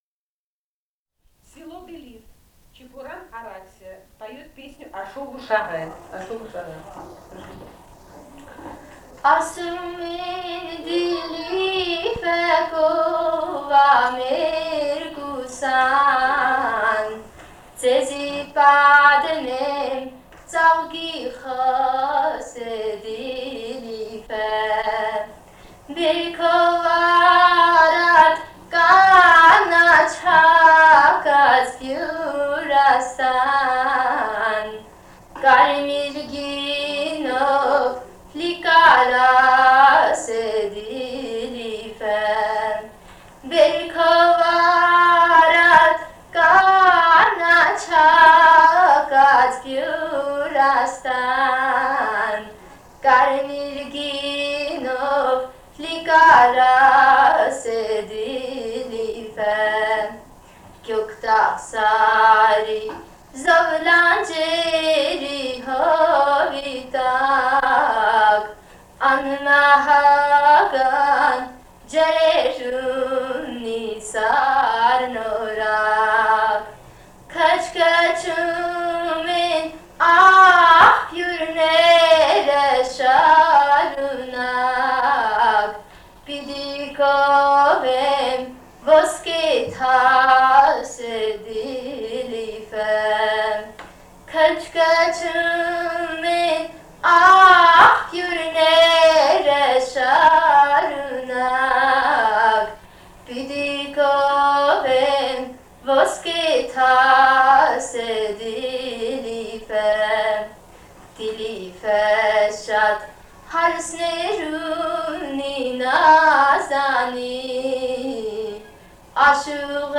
полевые материалы
Грузия, с. Дилифи, Ниноцминдский муниципалитет, 1971 г. И1310-20